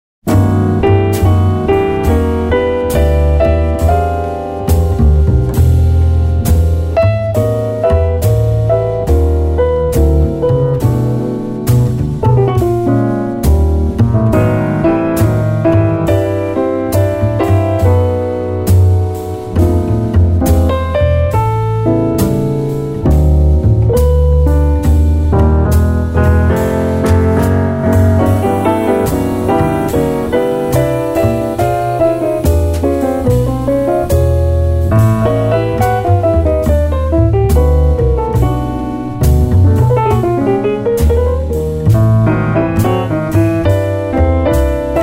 piano
bass
drums
Recorded at Avatar Studio in New York on April 26 & 27, 2010